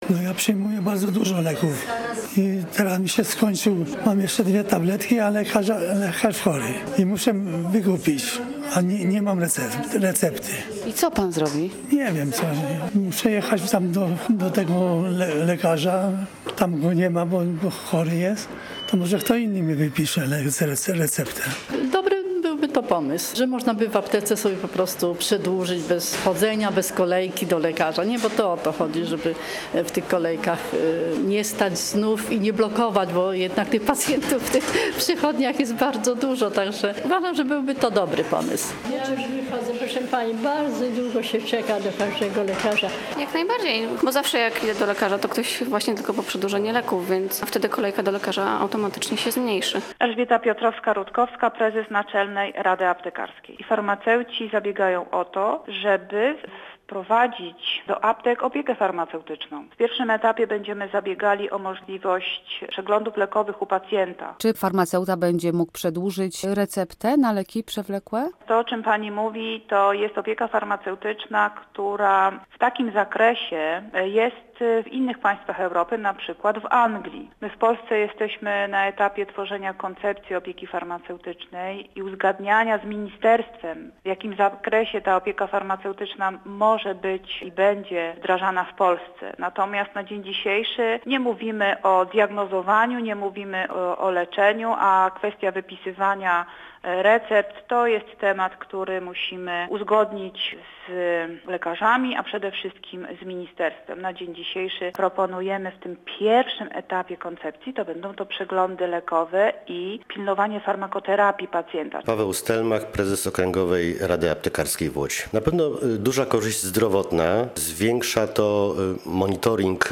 Posłuchaj relacji i dowiedz się więcej: Nazwa Plik Autor Opieka farmaceutyczna audio (m4a) audio (oga) ZDJĘCIA, NAGRANIA WIDEO, WIĘCEJ INFORMACJI Z ŁODZI I REGIONU ZNAJDZIESZ W DZIALE “WIADOMOŚCI”.